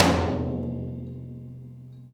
Tom Shard 05.wav